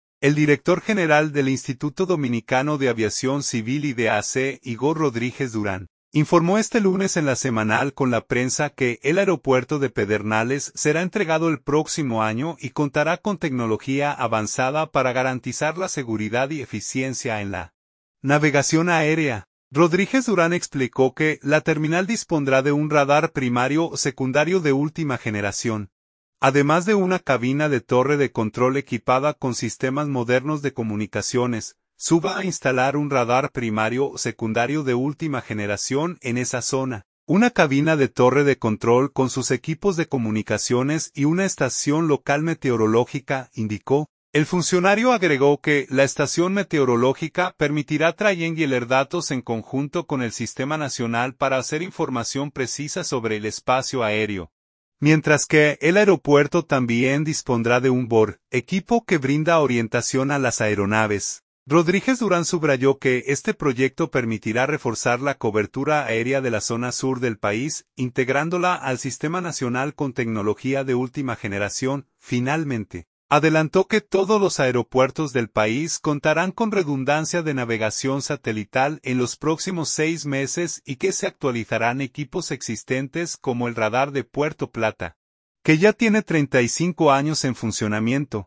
El director general del Instituto Dominicano de Aviación Civil (IDAC), Igor Rodríguez Durán, informó este lunes en La Semanal con la Prensa que el aeropuerto de Pedernales será entregado el próximo año y contará con tecnología avanzada para garantizar la seguridad y eficiencia en la navegación aérea.